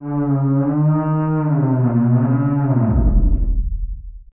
MOAN EL 05.wav